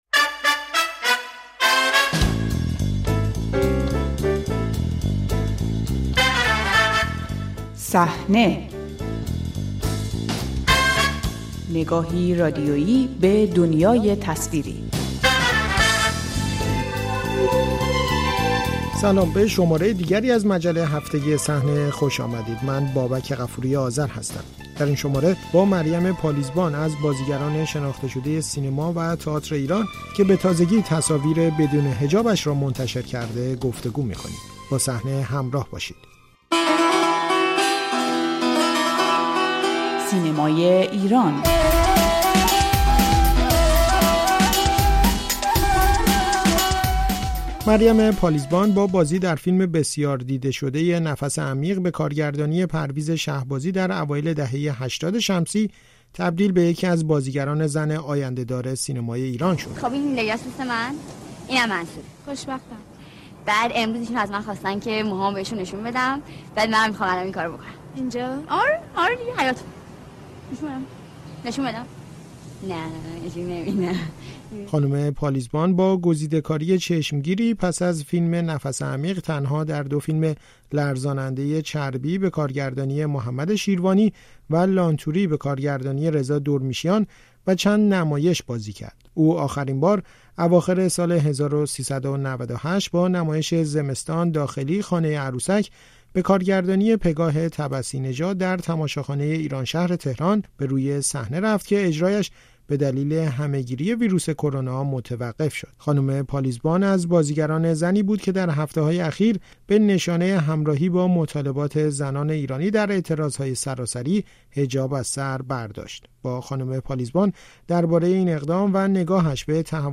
گفت‌وگو با مریم پالیزبان، بازیگری که حجاب را کنار گذاشت